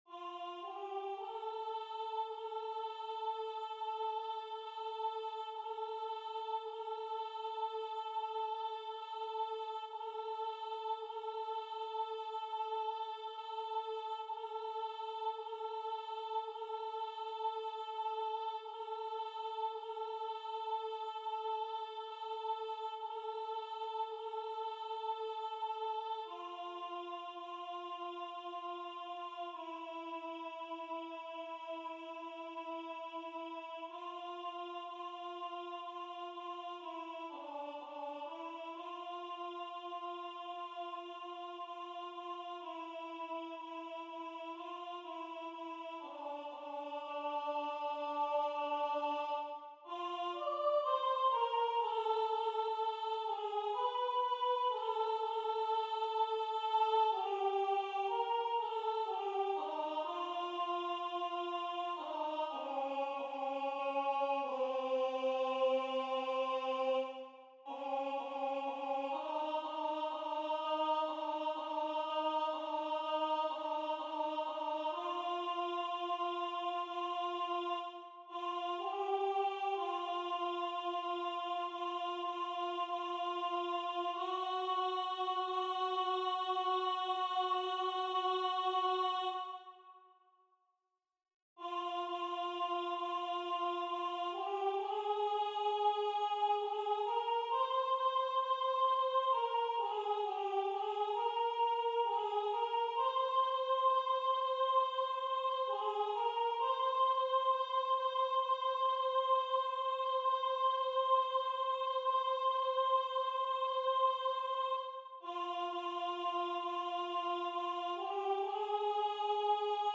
Alto 1